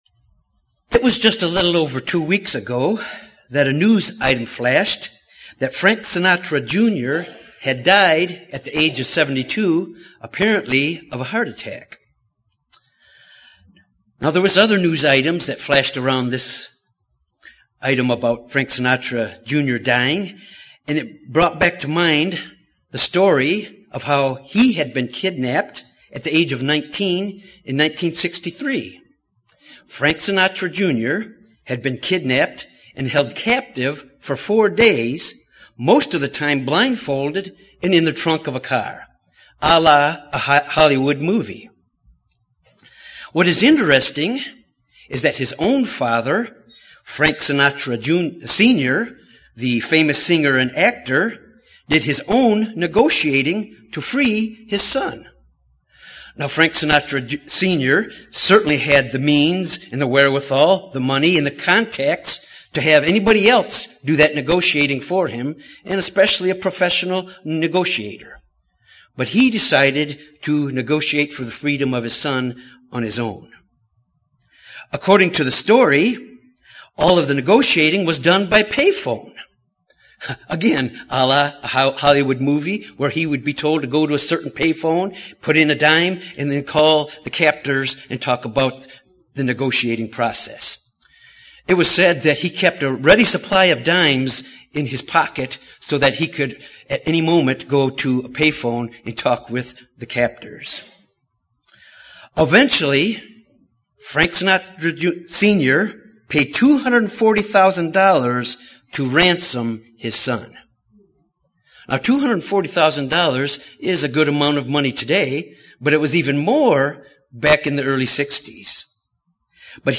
Jesus Christ paid the price of our redemption. This sermon examines the significance of the Passover and why it is so important.